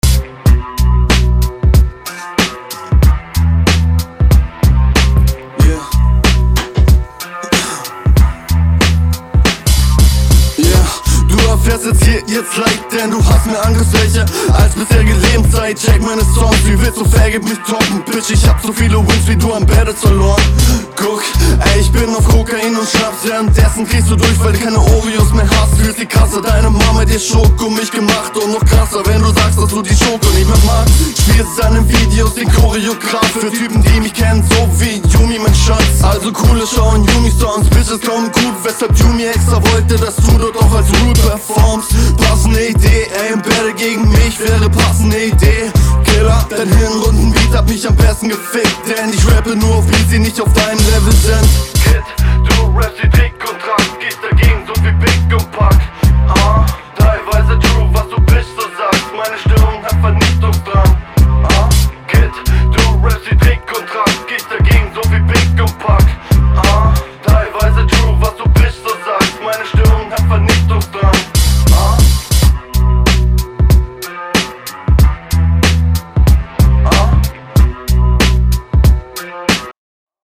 Hier ist das Soundbild besser, ich mag den Flow hier auch etwas mehr, das klingt …